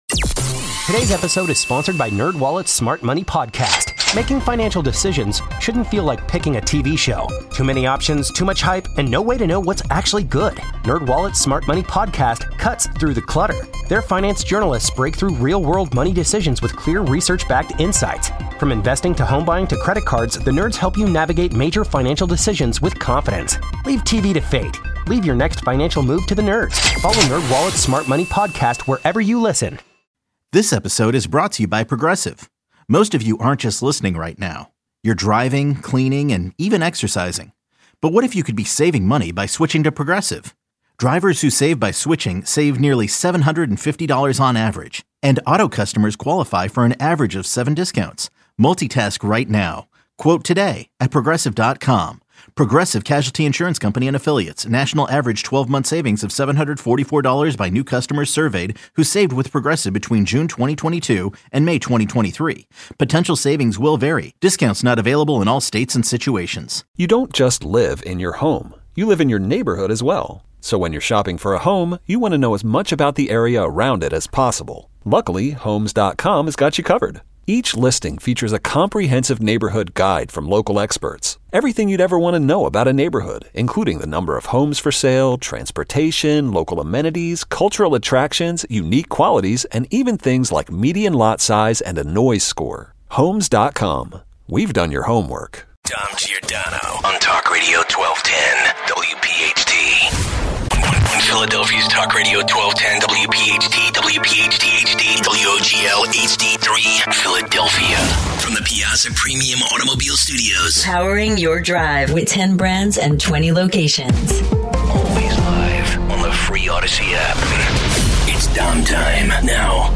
2 - Should we be tougher on Ukraine after this? 205 - More on the fallout in the White House today. More reaction. Your calls. 215